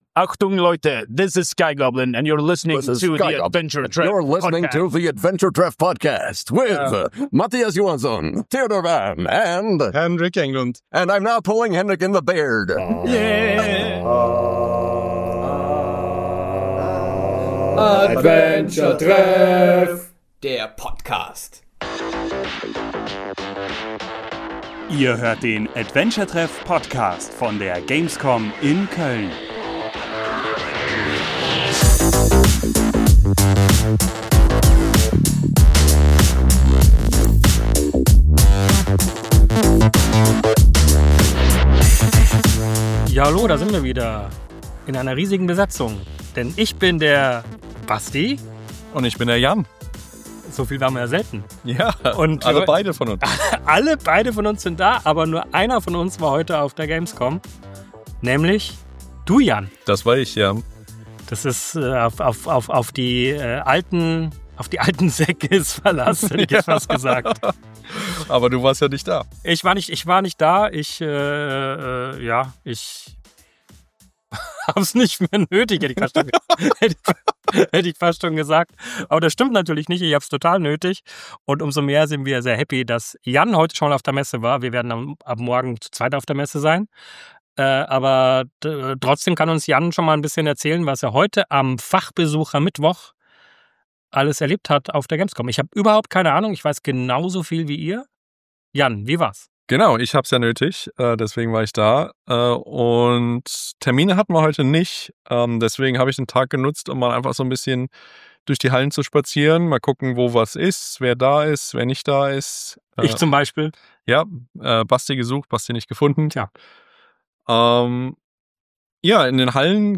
Podcast vom Fachbesucher-Mittwoch der gamescom 2025